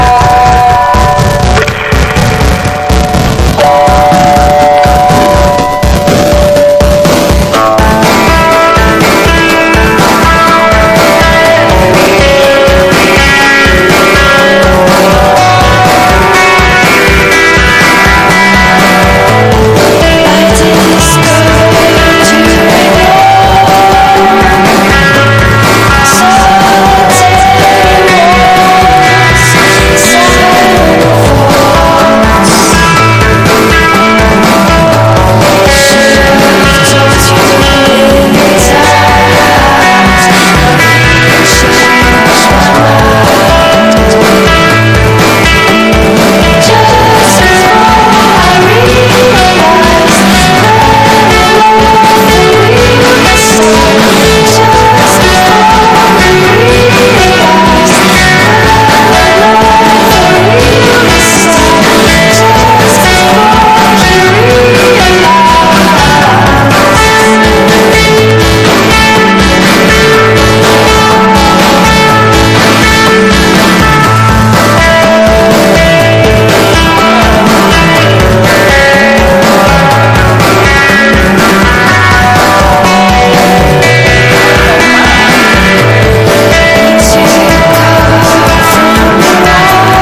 1. 10s ROCK >
INDIE DANCE